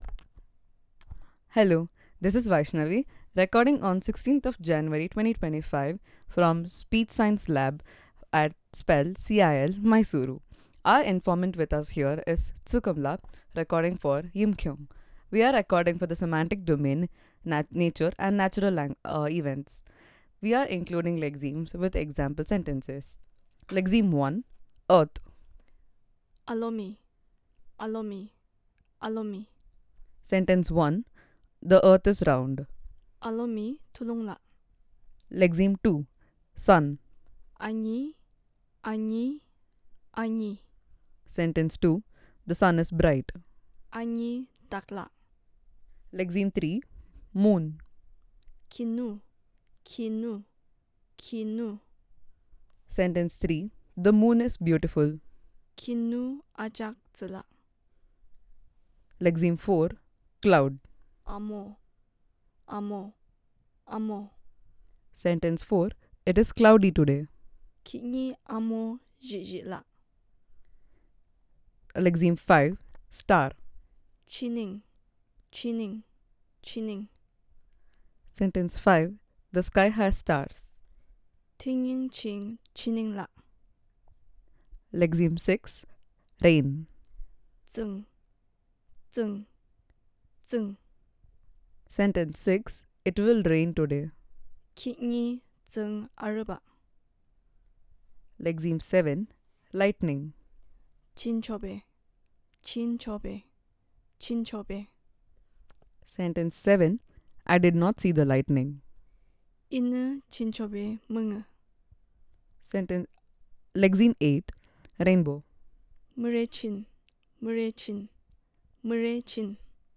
NotesThis is an elicitation of words related to Celestial bodies and Earth